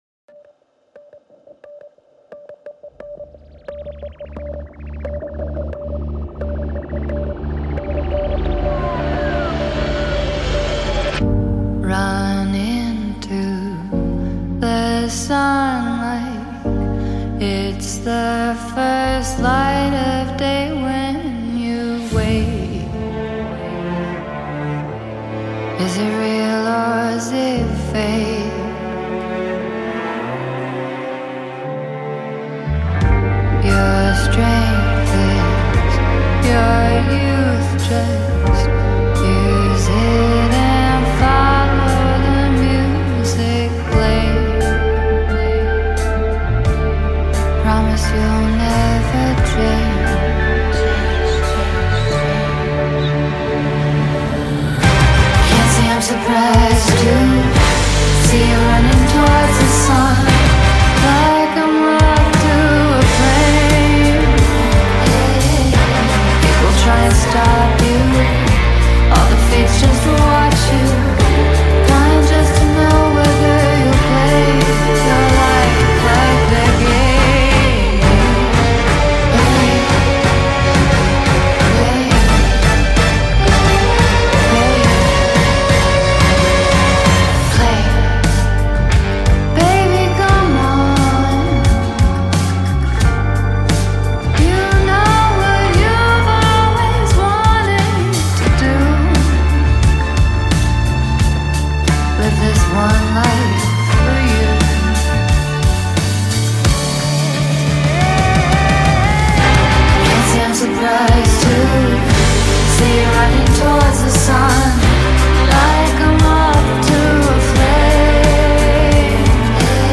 soul-stirring song